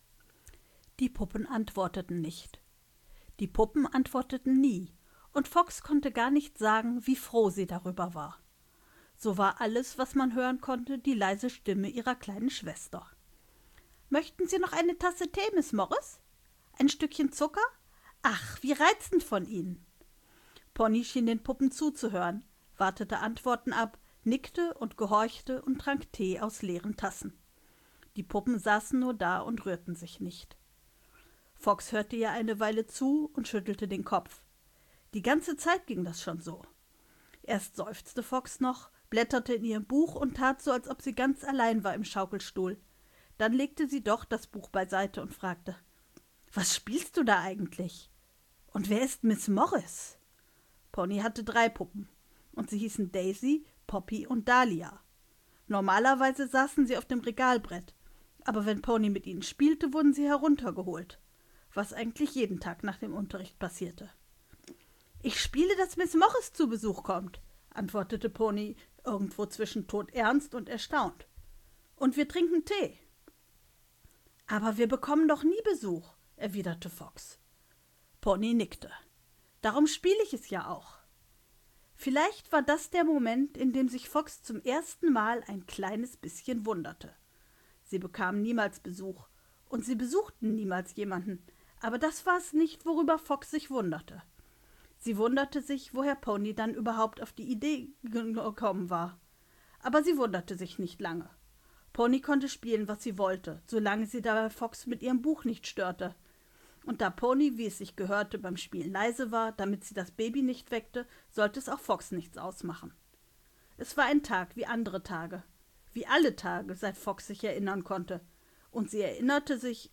Hoerprobe-Die-vierte-Wand.ogg